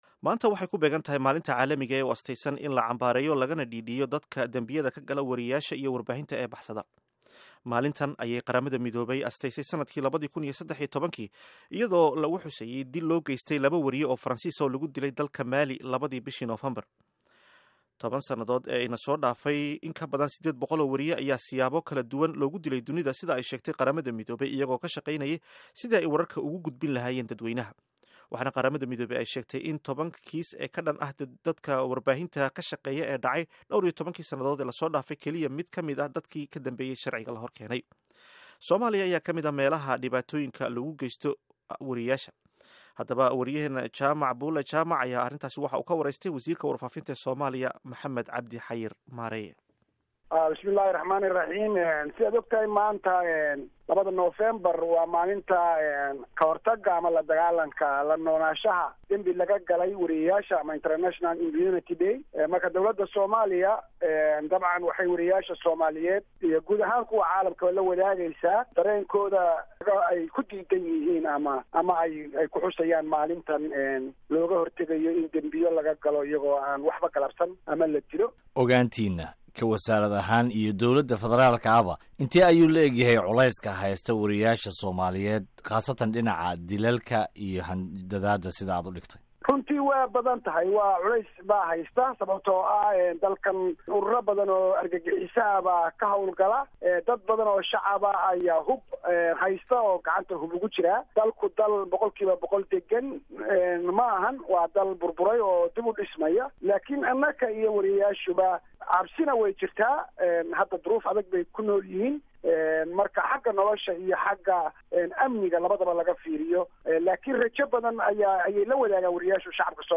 Dhageyso Wareysiga W. Warfaafinta